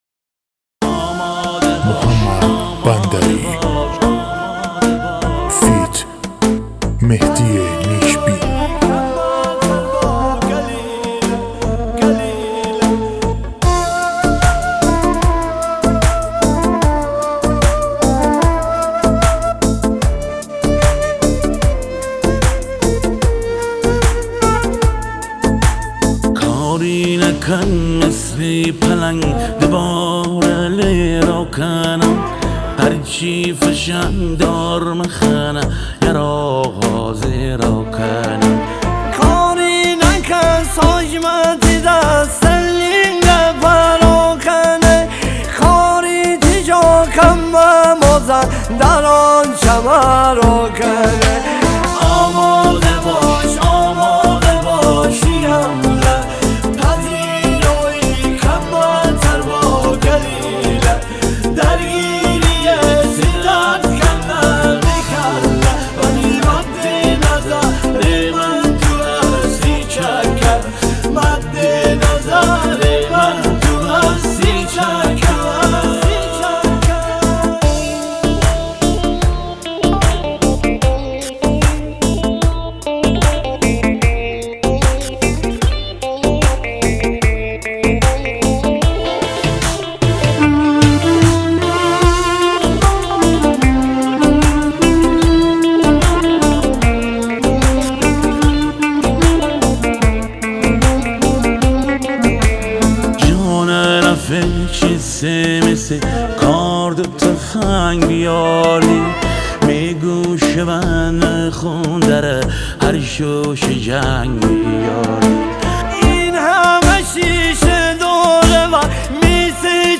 مازندرانی